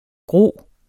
Udtale [ ˈgʁoˀ ]